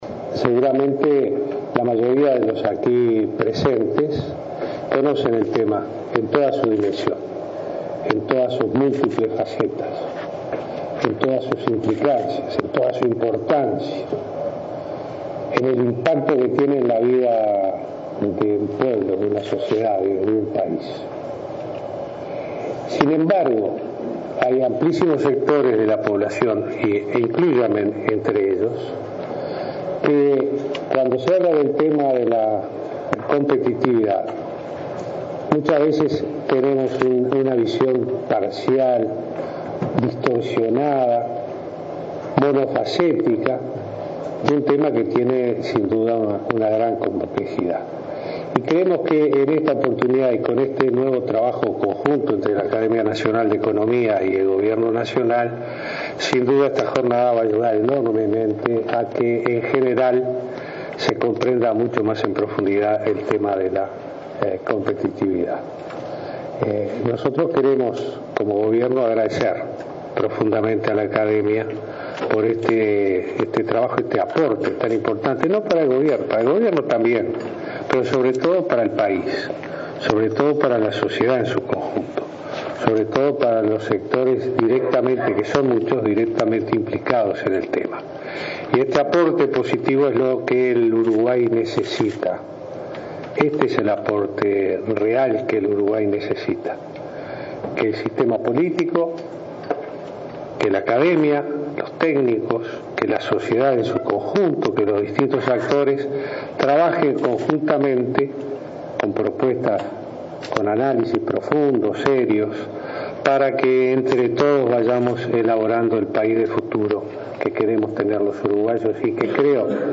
“Hay amplísimos sectores de la población —inclúyanme entre ellos— que cuando se habla del tema de la competitividad, muchas veces tienen una visión parcial”, señaló Tabaré Vázquez en una jornada de análisis entre el Gobierno y la Academia de Economía. Dijo que este tema implica "una gran complejidad” y destacó la importancia del “aporte y análisis profundo entre diferentes sectores para elaborar el país de futuro”.